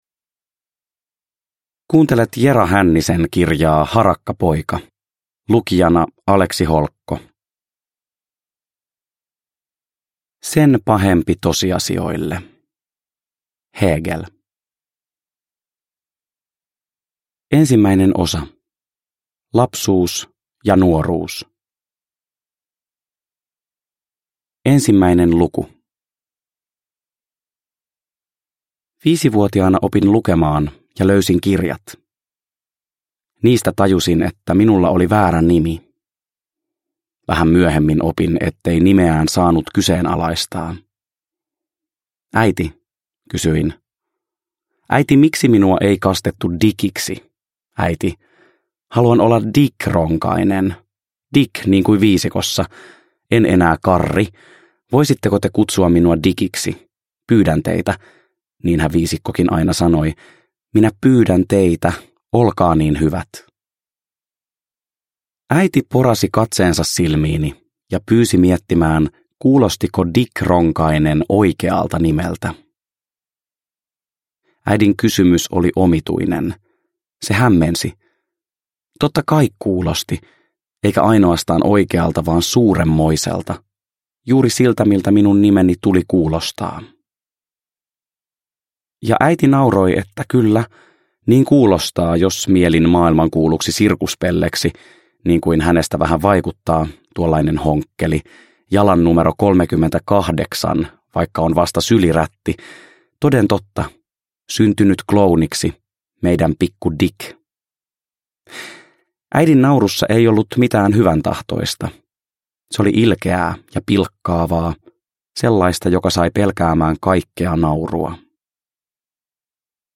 Harakkapoika – Ljudbok – Laddas ner